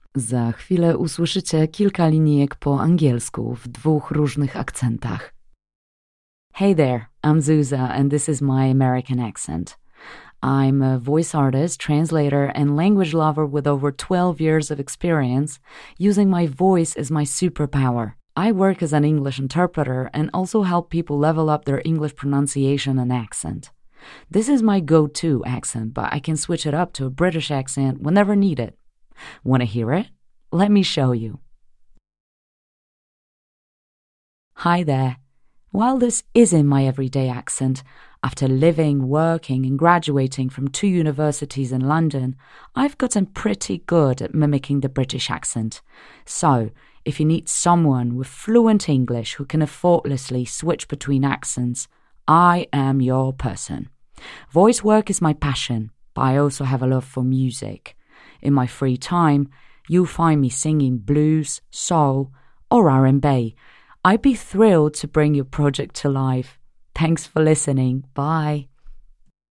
Female 30-50 lat
Voice artist with a warm voice that sounds excellent in mid-range registers. Records professional English voiceovers with both American and British accents.
Nagranie lektorskie w języku angielskim - akcent amerykański i brytyjski